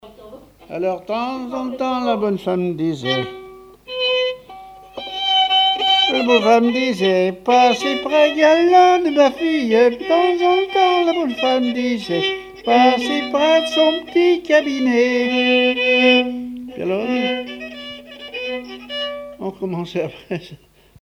branle
Thème : 0074 - Divertissements d'adultes - Couplets à danser
répertoire musical au violon
Pièce musicale inédite